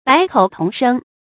百口同声 bǎi kǒu tóng shēng 成语解释 大家所说的都一样。